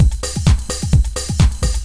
Jive Beat 3_129.wav